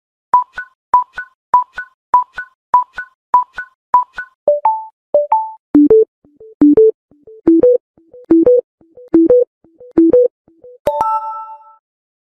History Of Galaxy Charging Sound